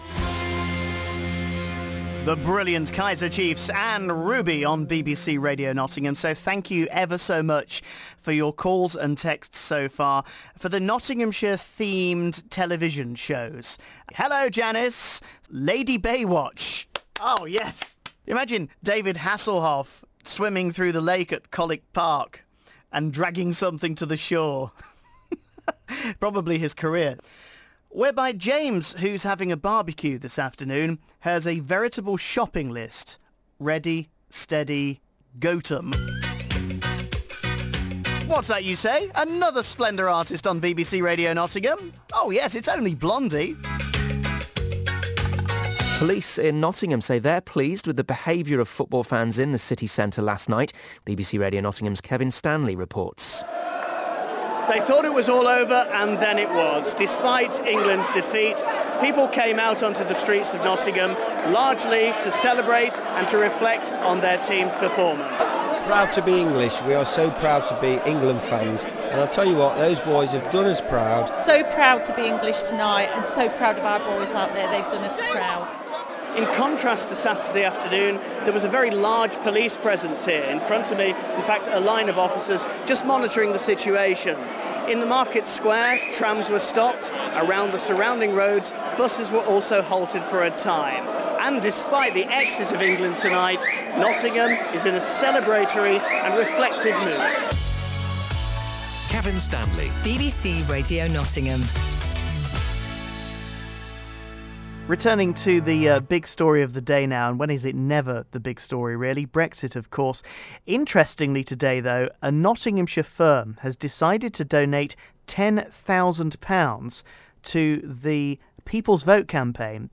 Showreel
A versatile voice